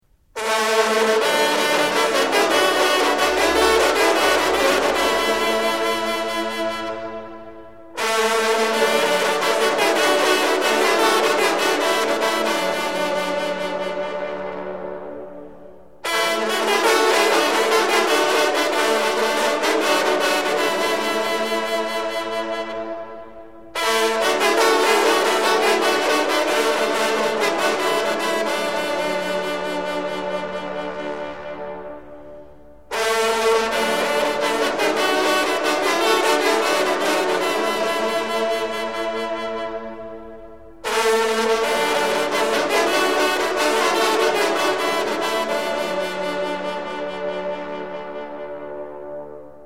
LES SONNERIES TROMPE DE CHASSE - Association Départementale des Chasseurs de Grand Gibier de l'Ain
Les fanfares du brevet
cor-de-chasse-la-saint-hubert.mp3